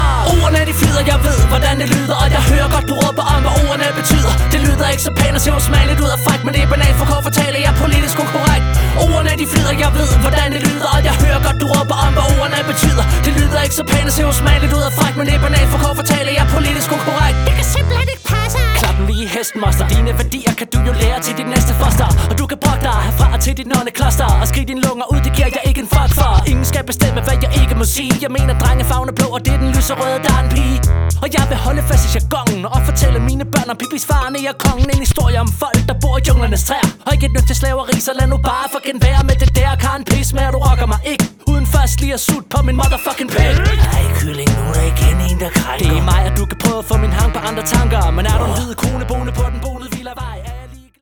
• Hip hop